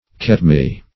Search Result for " ketmie" : The Collaborative International Dictionary of English v.0.48: Ketmie \Ket`mie"\, n. (Bot.) The name of certain African species of Hibiscus , cultivated for the acid of their mucilage.